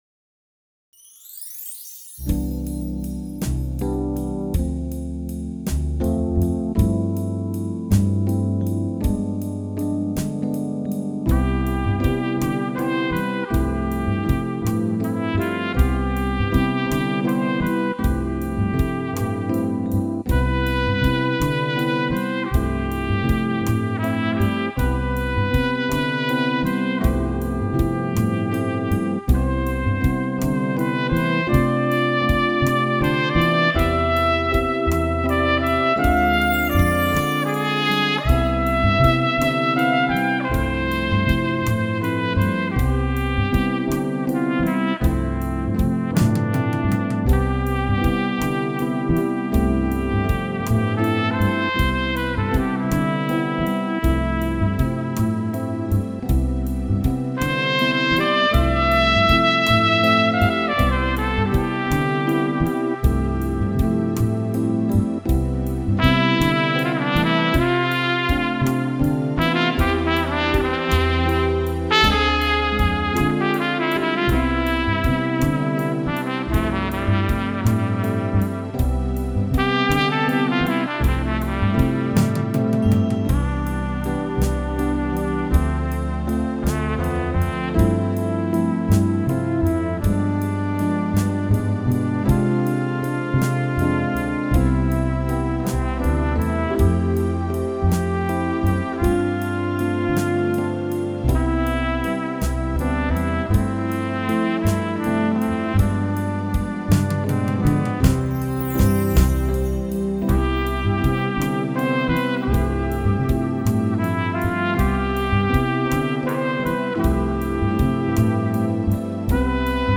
Instrumentaal nummer